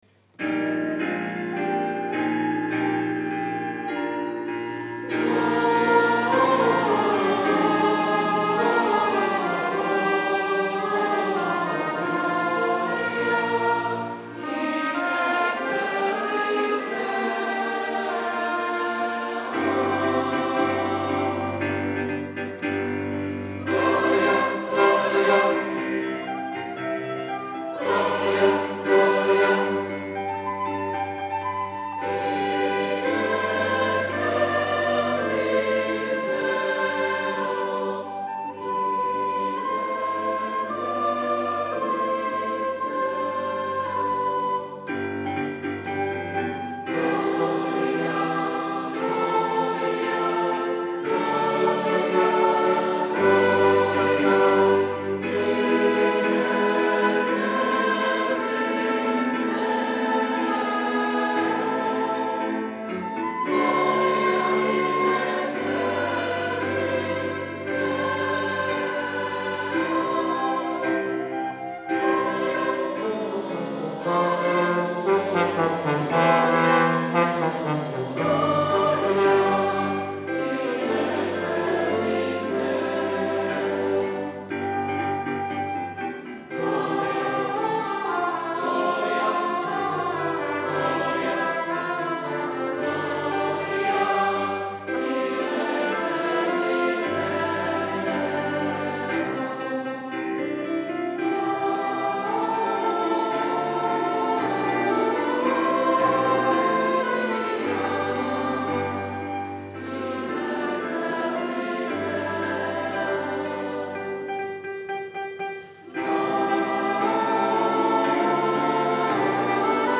Pěvecký sbor DPP | Pěvecký sbor